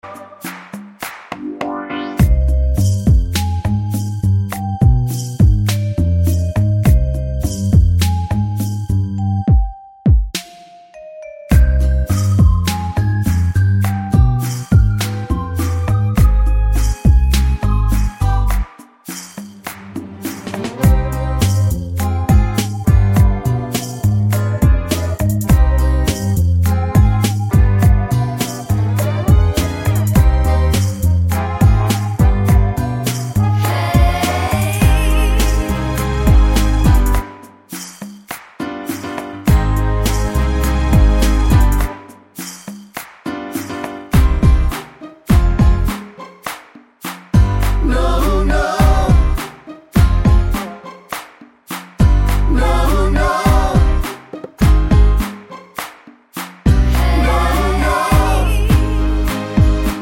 No Lead Vocals Soundtracks 3:33 Buy £1.50